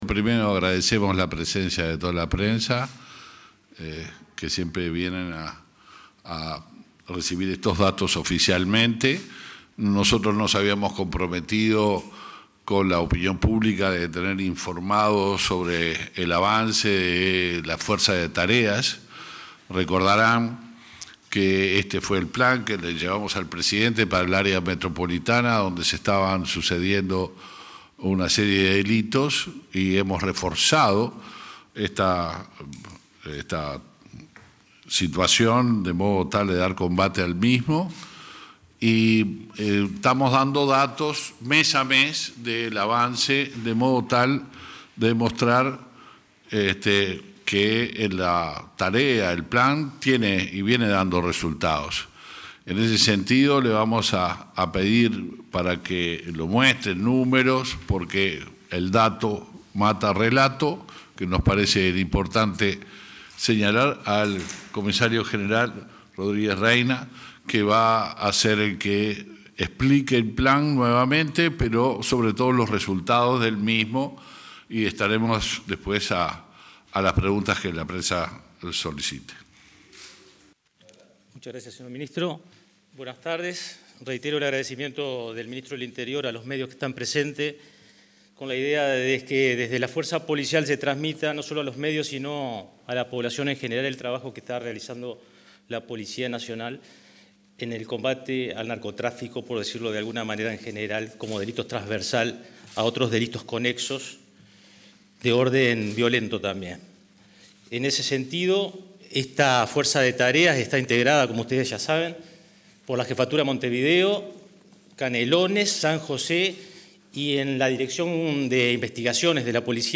Conferencia de prensa por la presentación de datos del plan estratégico del Ministerio del Interior
Participaron, entre otros jerarcas, el ministro Luis Alberto Heber, y el director de Interpol, Juan Rodriguez.